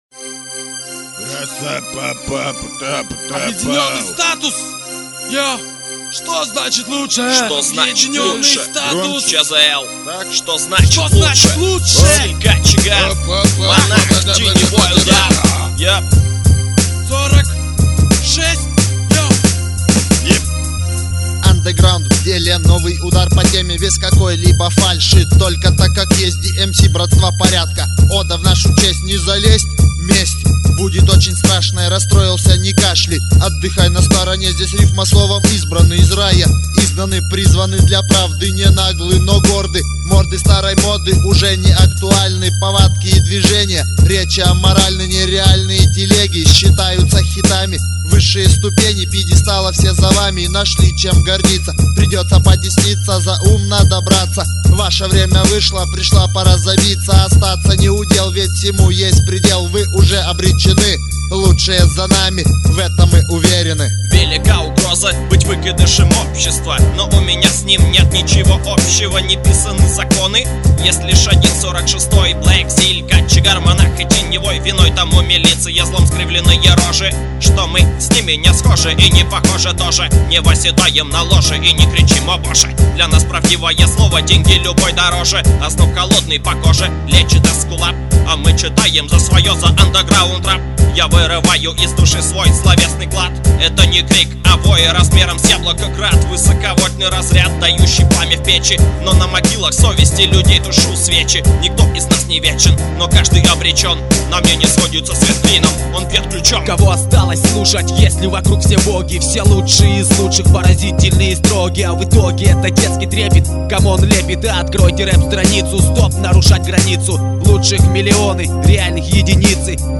другая хип-хоп, rap музыка